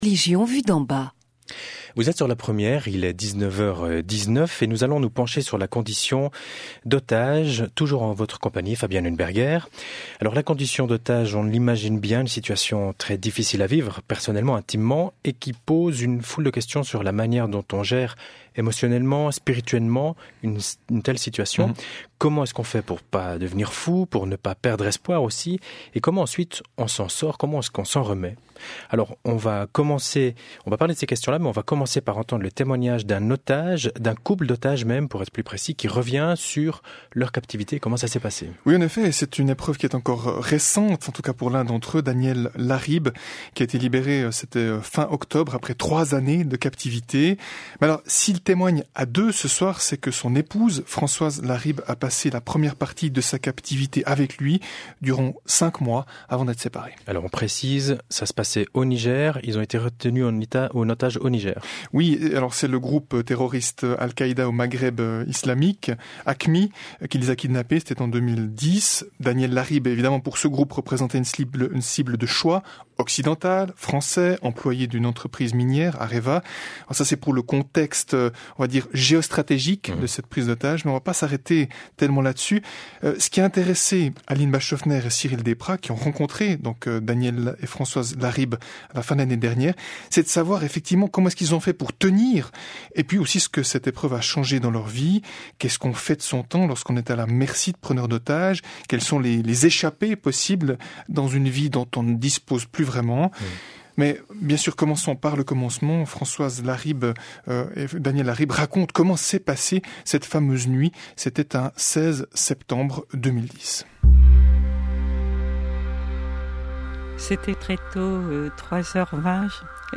C’était le thème de l’émission Hautes Fréquences diffusée sur la radio suisse RTS début février.
Et de se demander comment on gère cette situation du point de vue émotionnel et spirituel notamment. Pour y répondre, le témoignage d’un couple d’otages enlevé au Niger qui revient sur cette épreuve.